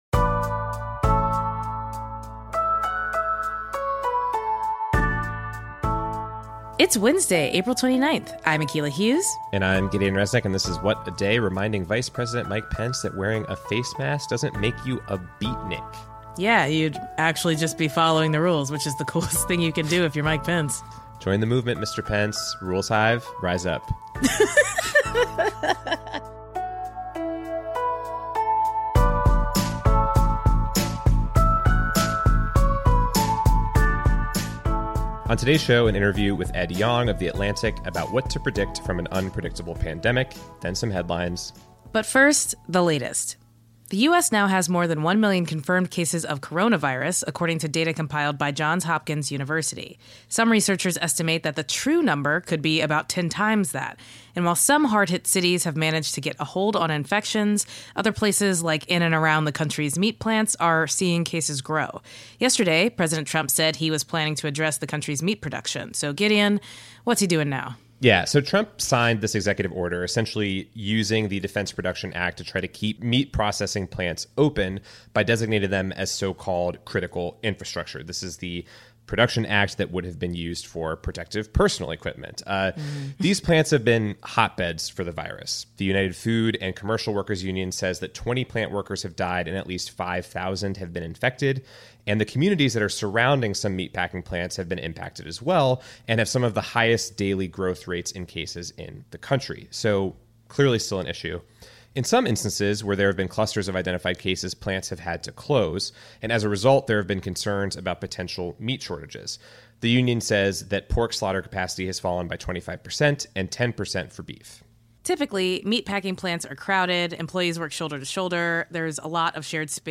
We interview Ed Yong, science writer at The Atlantic, about what we know about the virus so far, and what we're still learning.